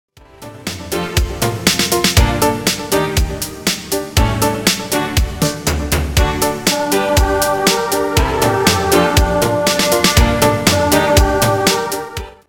・monoをチェックして音声をパンした例